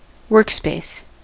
Uttal
Uttal US